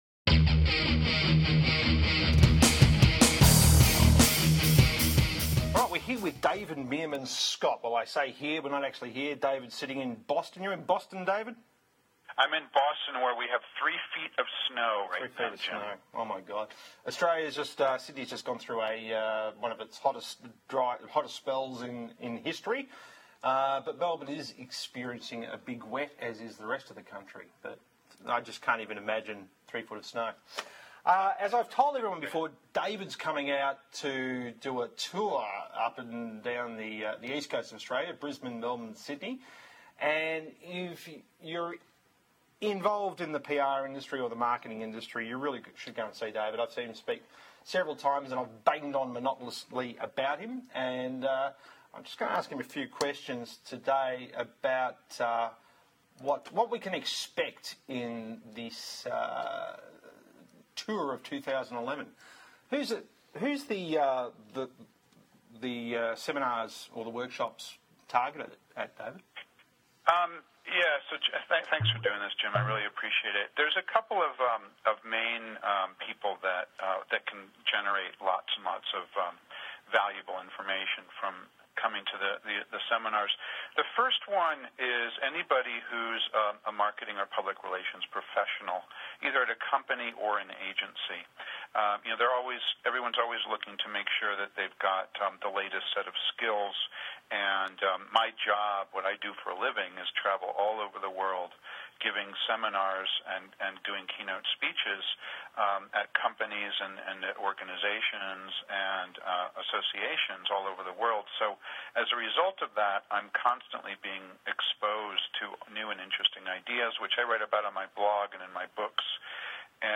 As promised I have uploaded the interview with David Meerman Scott. It is around 26 minutes long and I have put it on YouTube as a video for easy access, even though David is only on the phone .